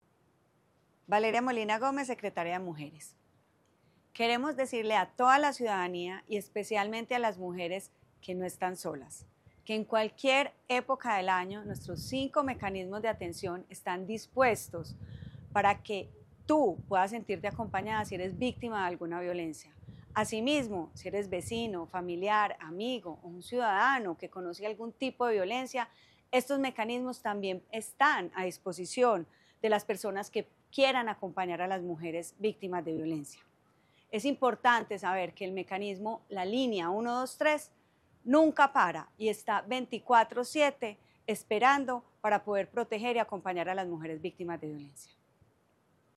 Palabras de Valeria Molina Gómez, secretaria de las Mujeres